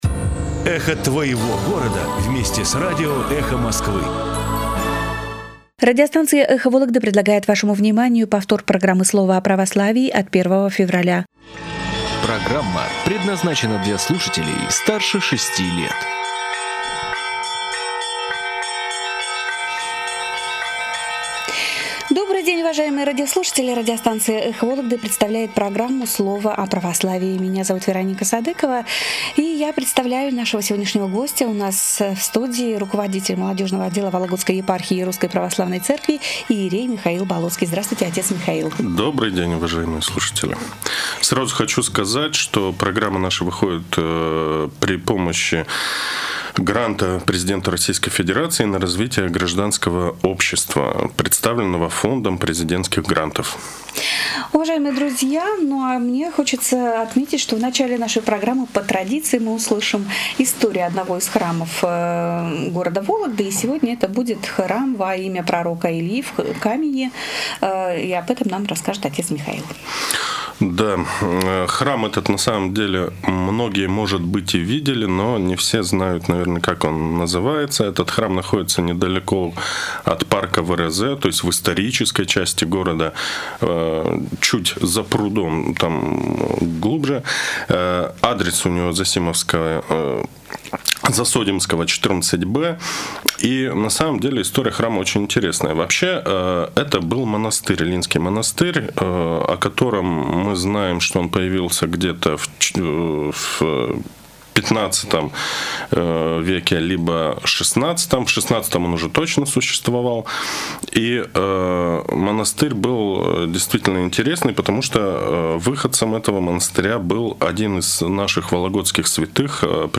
в прямом эфире радио "Эхо Вологды". Слово о Православии